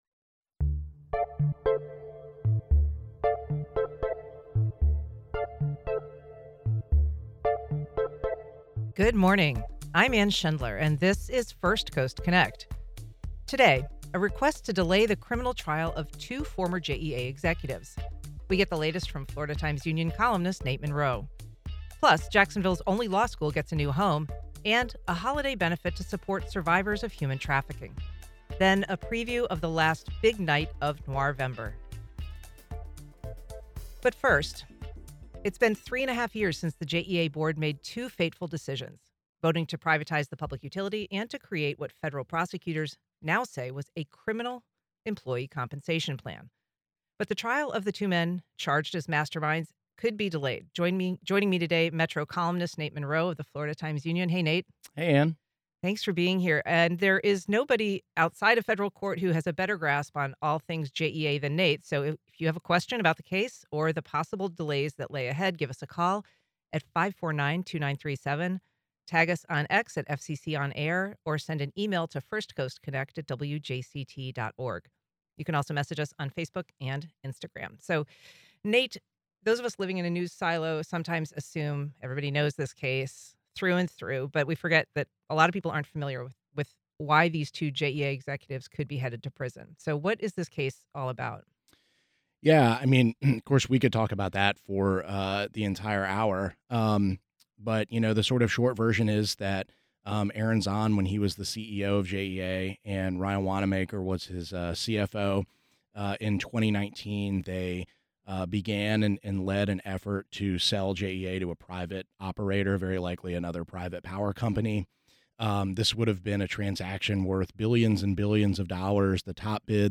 First Coast Connect is an hour-long call-in program that features local newsmakers, civic and community leaders, arts, activities across Jacksonville and Northeast Florida, along with spot news features and a weekly roundtable of local journalists.